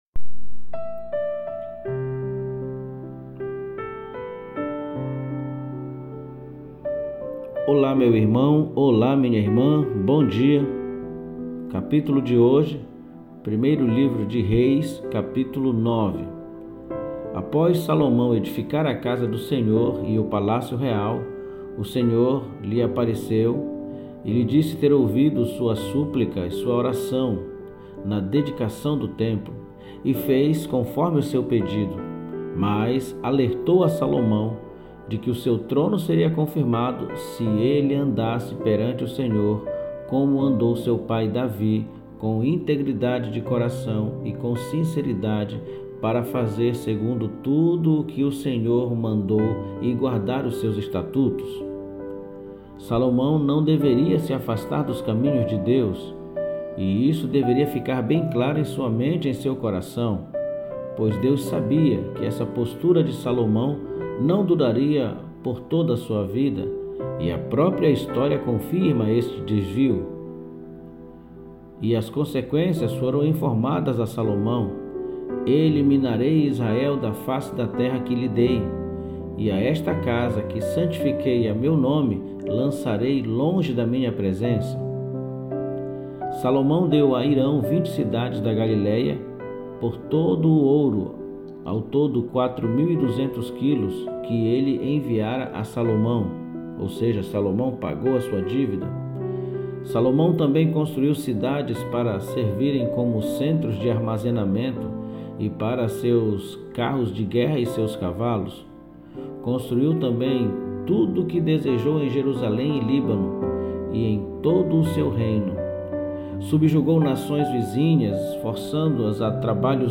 PODCAST DE MEDITAÇÃO BÍBLICA DE HOJE